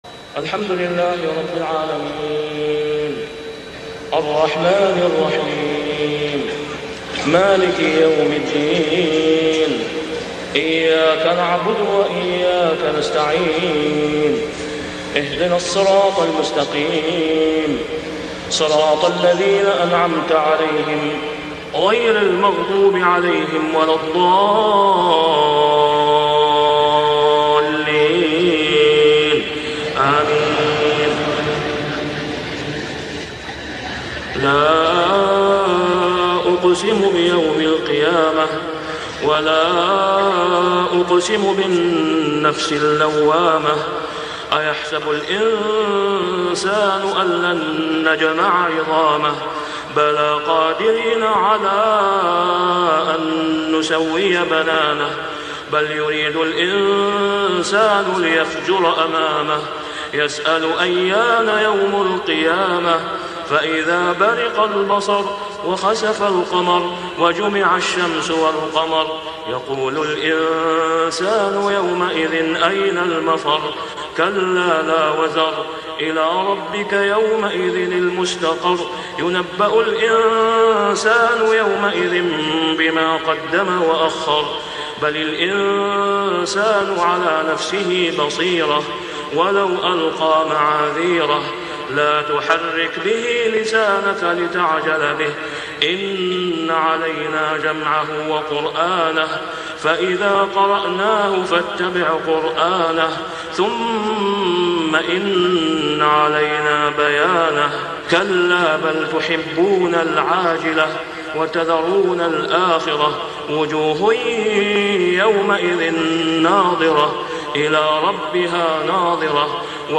( صلاة العشاء و العام غير معروف ) | سورة القيامة كاملة > 1420 🕋 > الفروض - تلاوات الحرمين